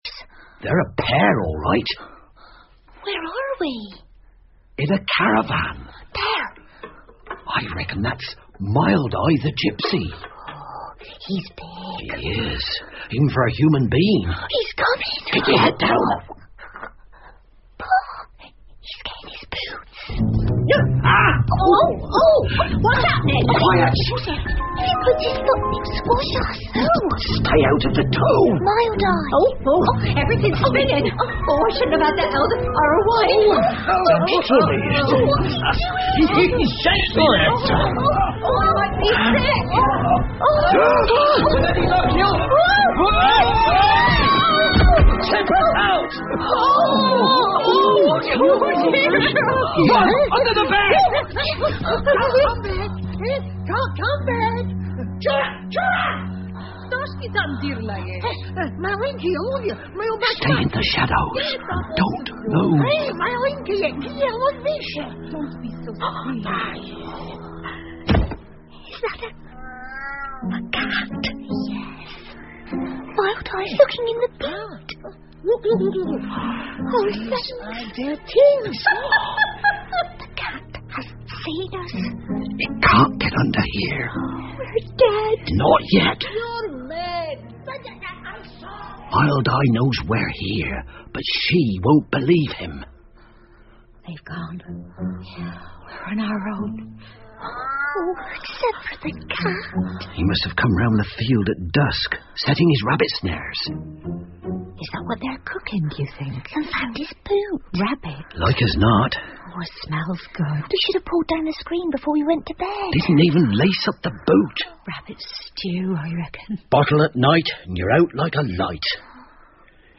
借东西的小人 The Borrowers 儿童广播剧 16 听力文件下载—在线英语听力室